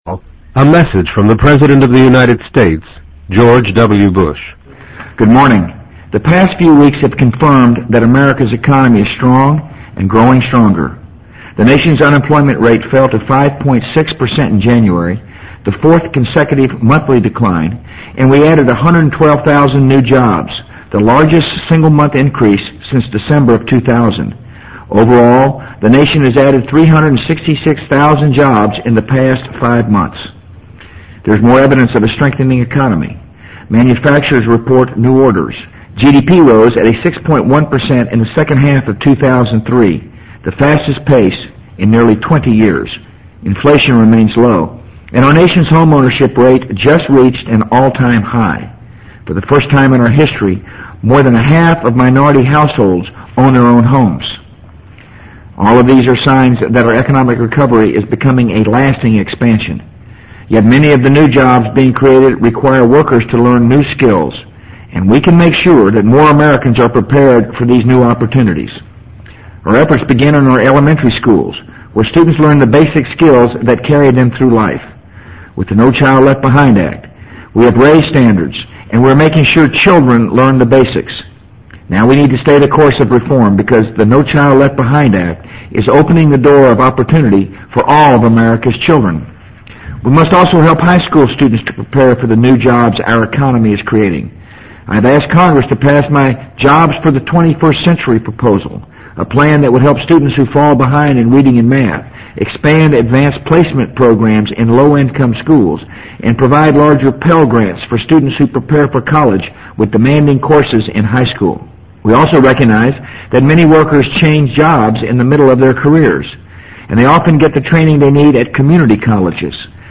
【美国总统George W. Bush电台演讲】2004-02-07 听力文件下载—在线英语听力室